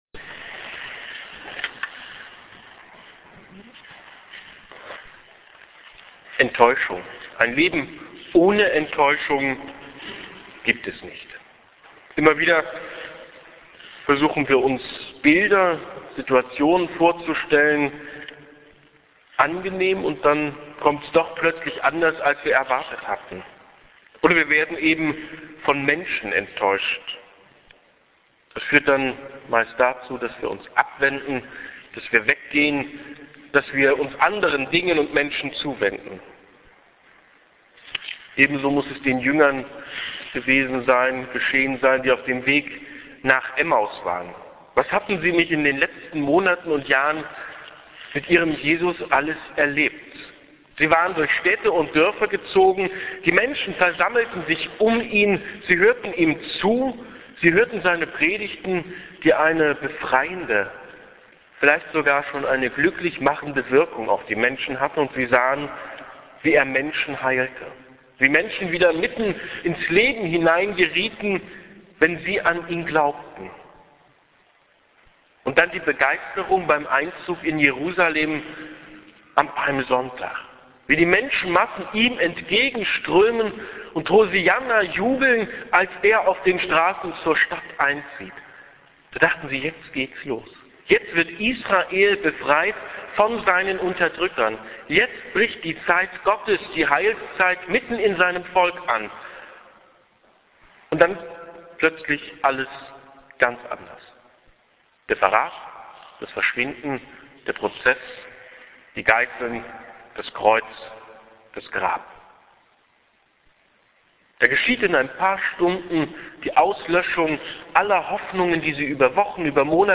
emmaus_ostermontag_hier-klickt-die-predigt.mp3